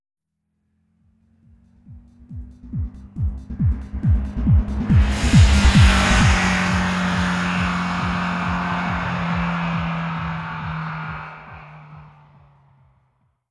Pasada de coche con música
coche
Sonidos: Música
Sonidos: Transportes
Sonidos: Ciudad